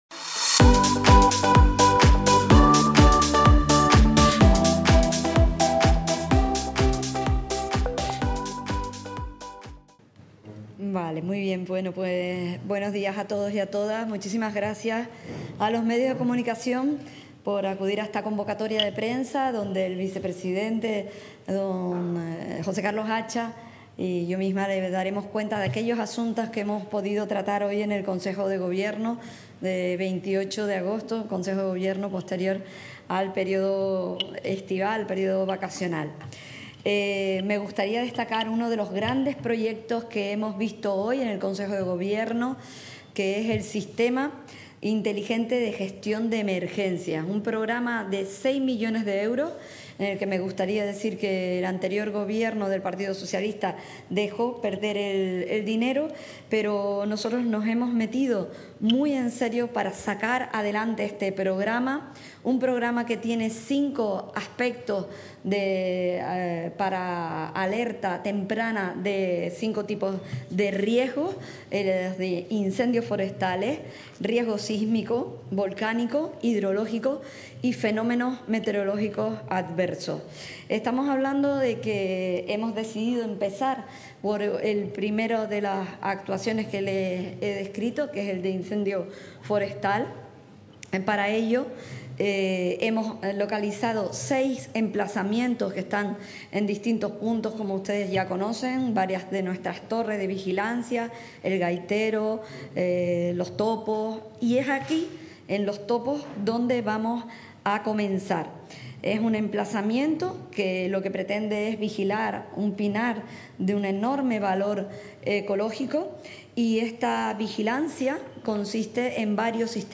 Recientemente emitido: Este miércoles 28 de agosto, a partir de las 11.30 horas, desde el Salón Noble del Palacio Insular, rueda de prensa para informar sobre los Acuerdos tomados en la sesión del Consejo de Gobierno.